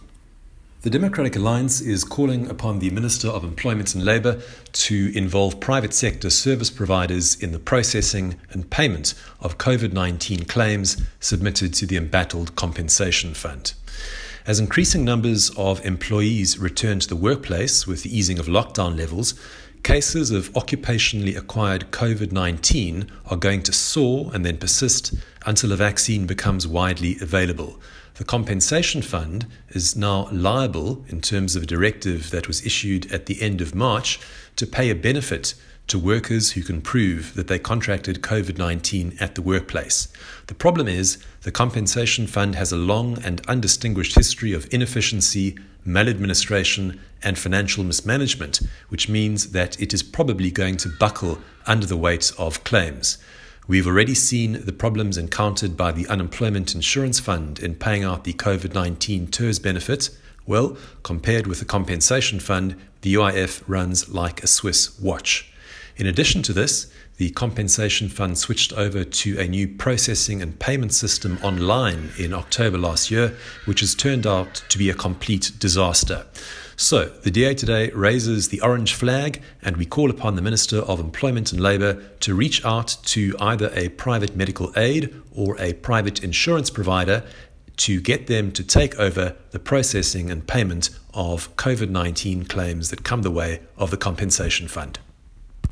The following statement was delivered today by the  DA Shadow Minister for Employment and Labour, Dr Michael Cardo MP during a virtual press conference.
soundbite by  Dr Michael Cardo MP.